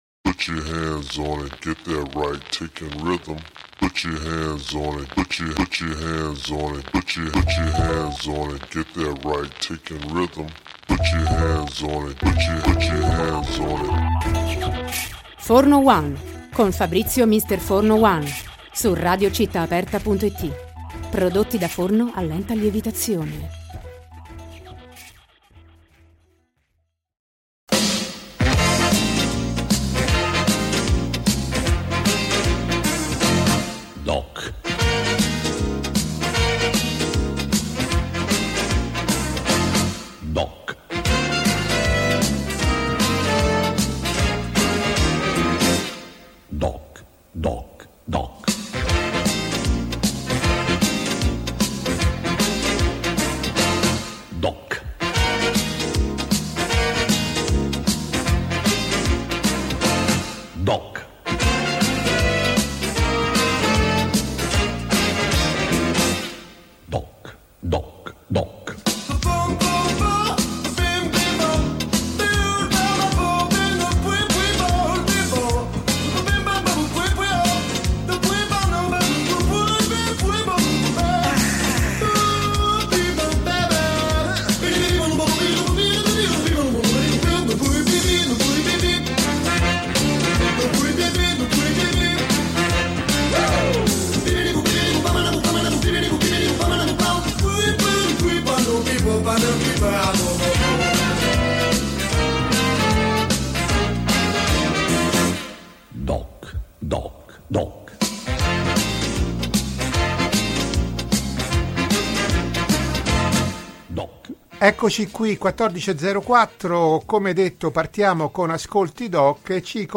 Ascolti DOC intervista Asakaira